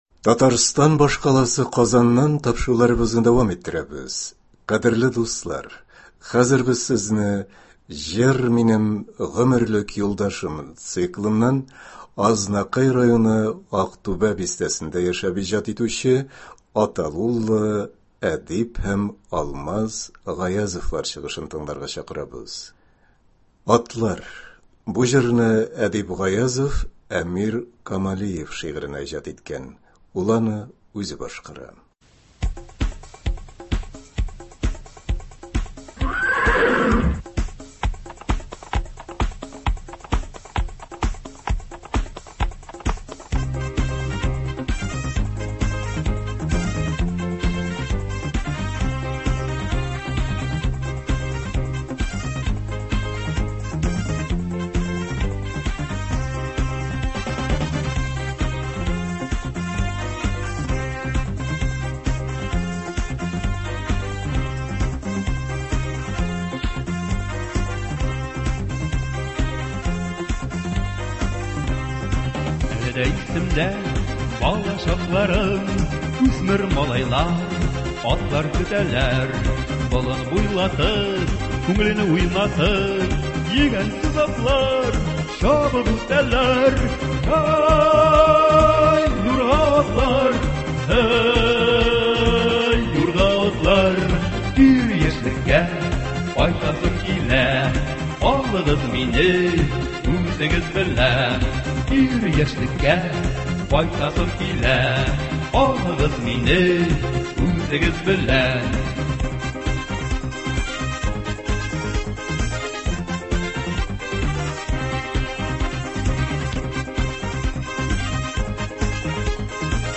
Концерт (14.12.20)
халык җырлары